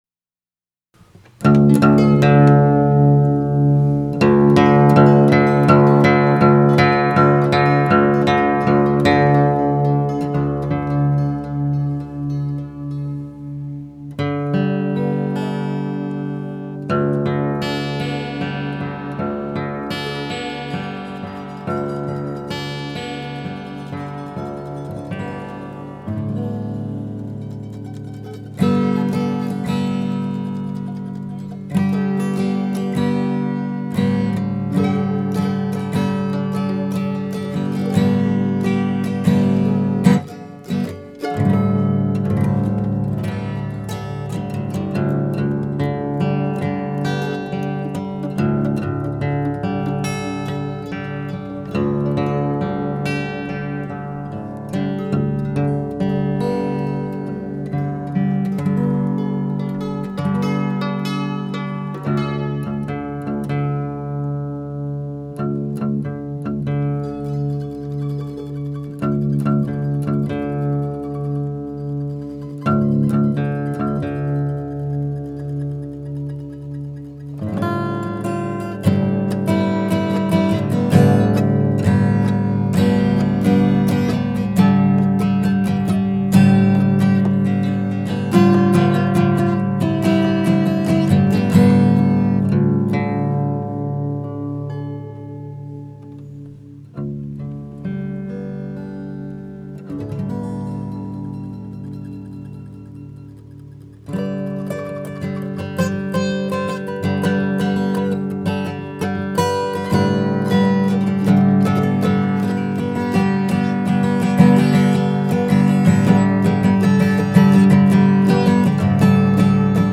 well, another collage type sound, and as most know, for me to get the instruments out, hit the red button, and post, is a monumentous achievement. enjoy, or not.